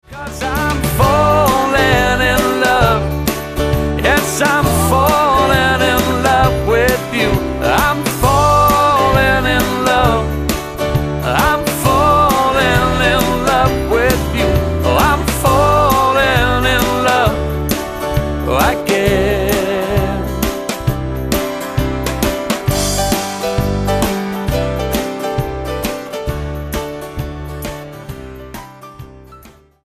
STYLE: Roots/Acoustic
Guitar-based, but also including some appealing keyboards.